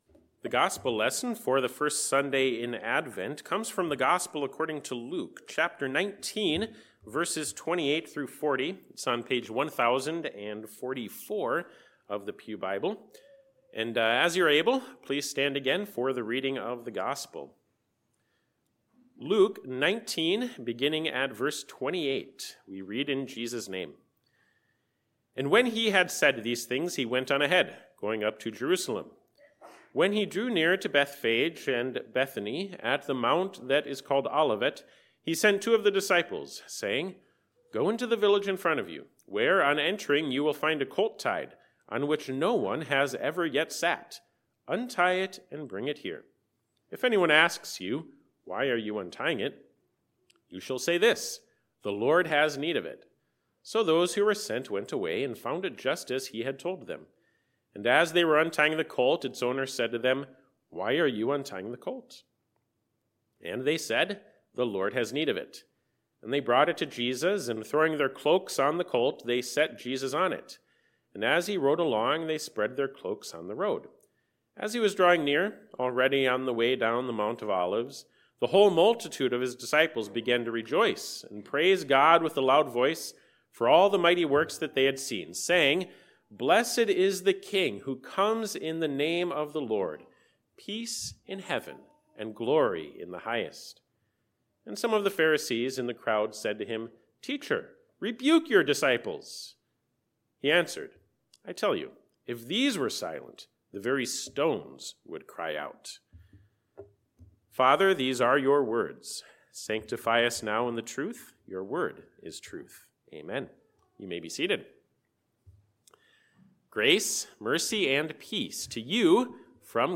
A pre-recorded audio message